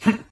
jump_02.ogg